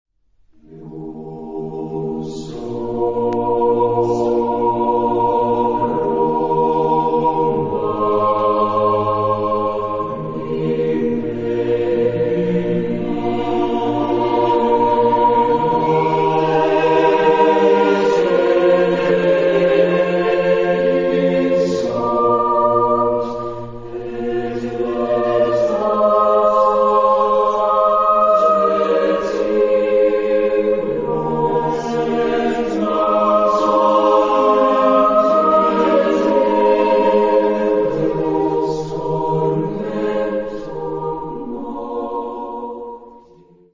Genre-Style-Form: Sacred ; Baroque ; Renaissance
Type of Choir: SSATB  (5 mixed voices )